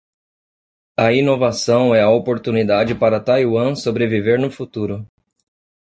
Pronunciado como (IPA)
/tajˈwɐ̃/